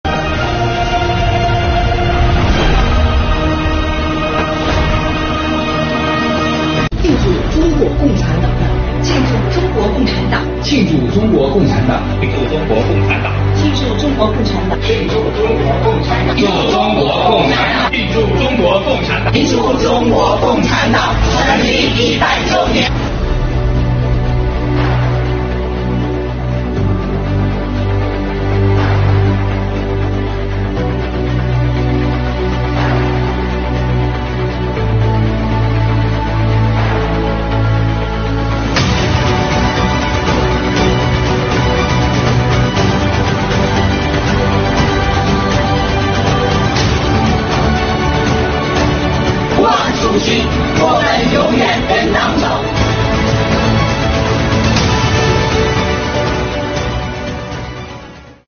为热烈庆祝建党百年，闵行区税务局的党员干部职工们结合学党史知识、过“政治生日”，践岗位承诺等，以满腔的热情向党诉说自己的心声，让我们一起来听听这些跨越时空的“初心对话”吧！